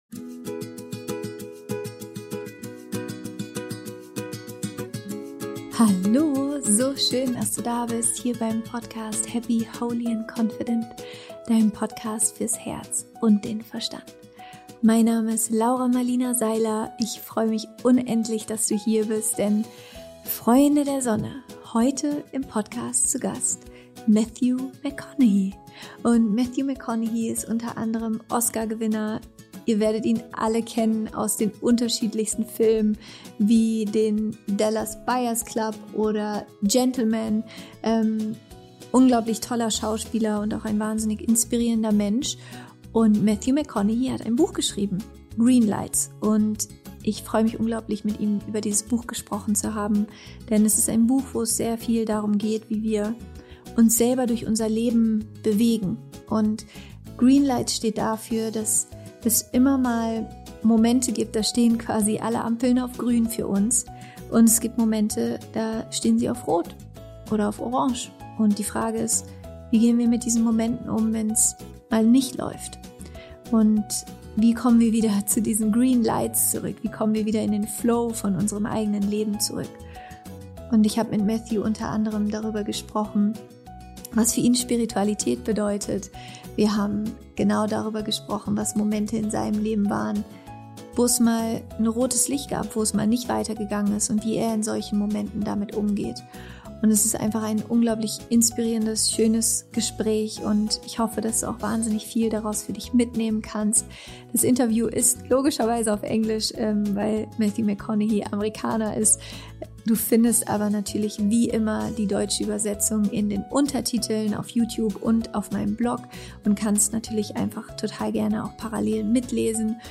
Wie du wieder in deinen Flow im Leben kommst – Interview Special mit Matthew McConaughey
Ich hatte vor ein paar Tagen die große Freude ein sehr inspirierendes Interview mit Matthew McConaughey zu führen, Oscar-Preisträger, erfolgreicher Schauspieler in Hollywood und auch Autor.